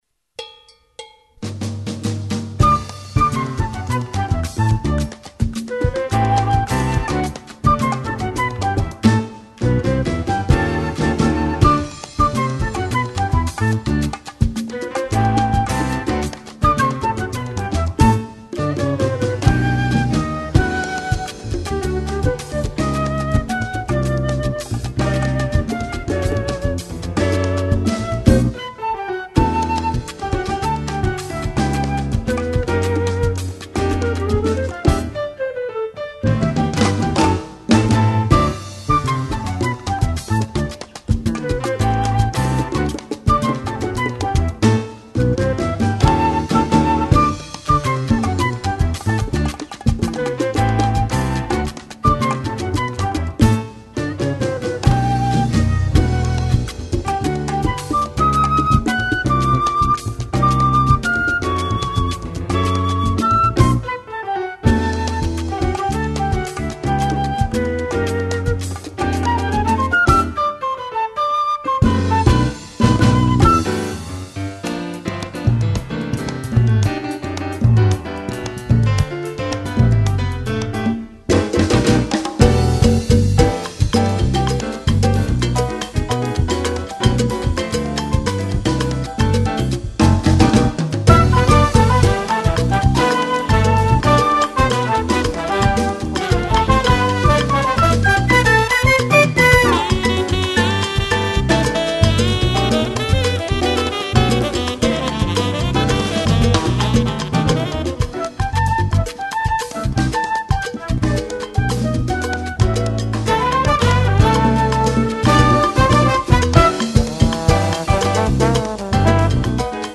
Category: combo (nonet)
Style: songo
Solos: open, percussion